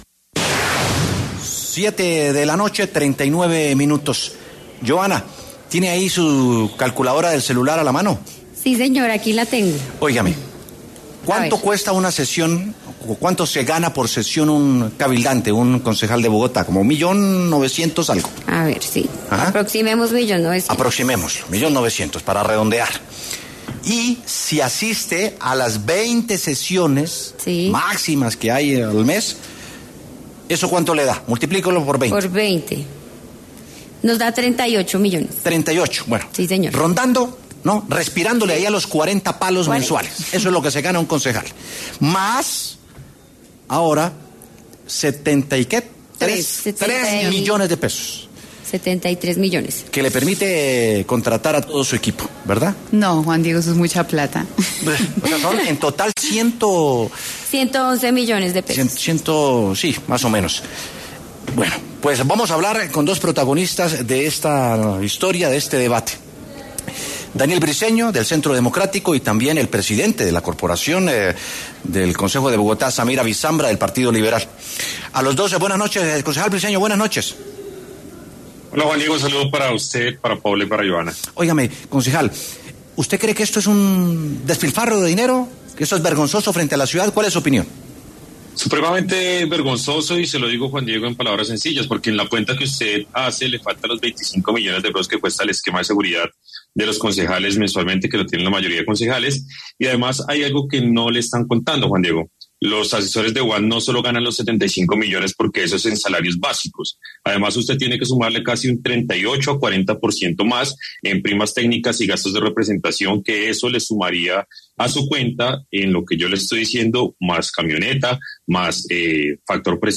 Daniel Briceño, del Centro Democrático, y Samir Abisambra, del Partido Liberal, estuvieron en W Sin Carrera para debatir sobre los gastos en personal que tendrán los concejales en Bogotá, que ascienden a 74 millones de pesos.